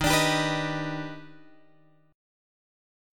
D# Augmented Major 7th